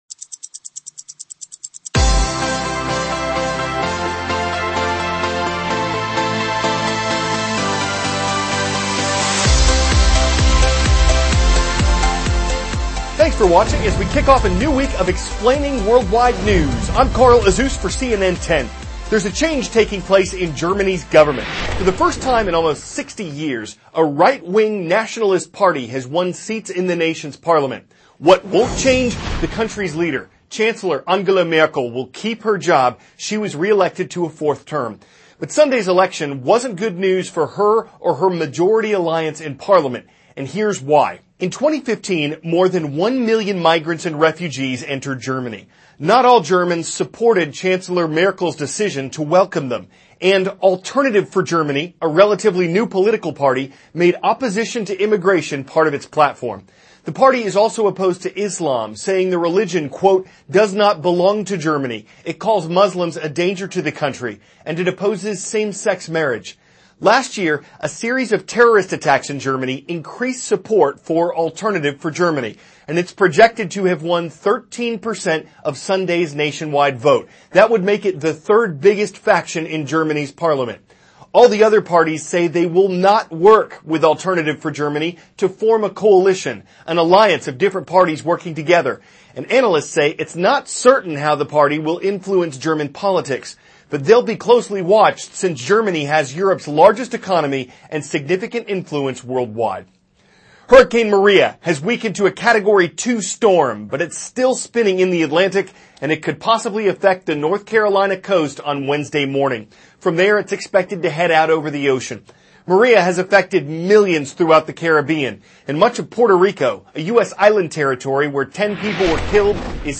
CARL AZUZ, cnn 10 ANCHOR: Thanks for watching.